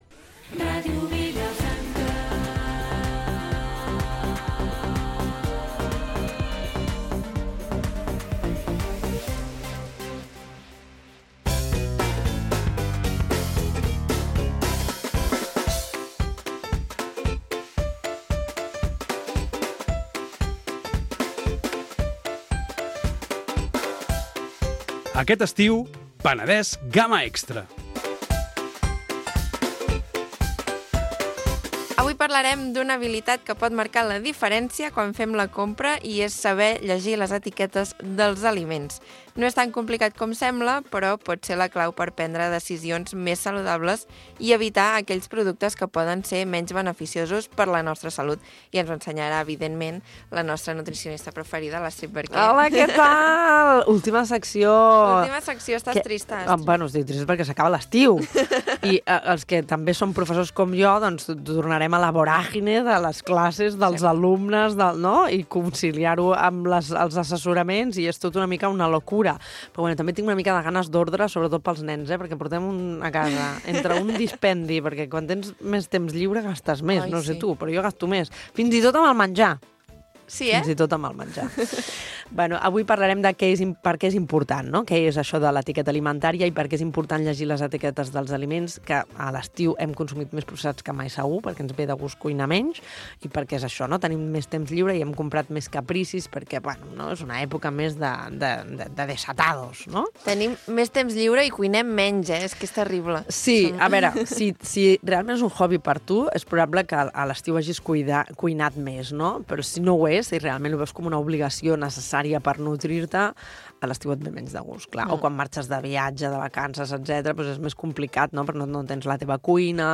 Magazín diari d'estiu